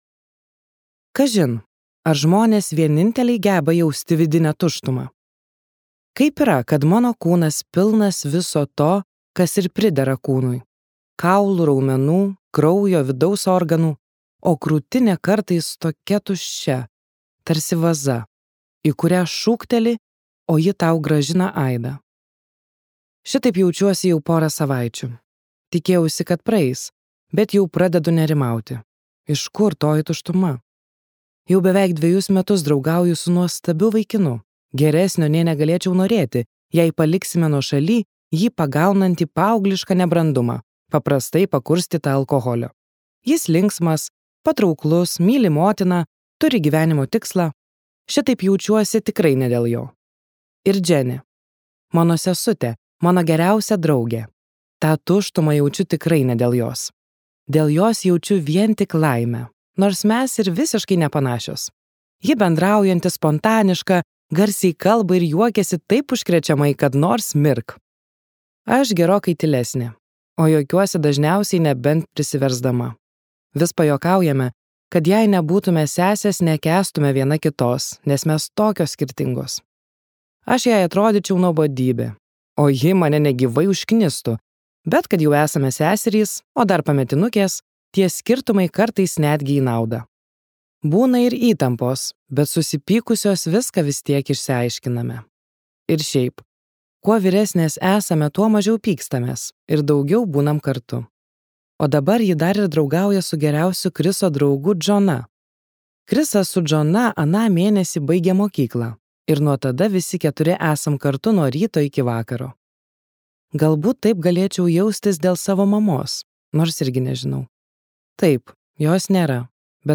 Jei ne tu | Audioknygos | baltos lankos